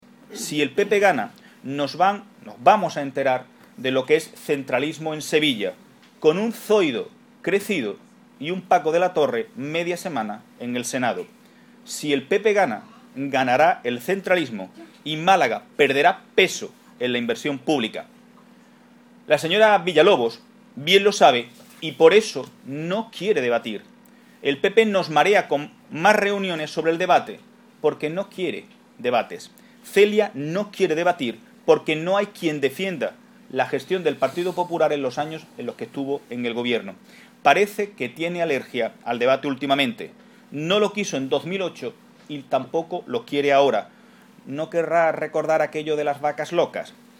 Heredia ha ofrecido esta mañana una rueda de prensa junto a la cabeza de cartel de los socialistas al Senado, Pilar Serrano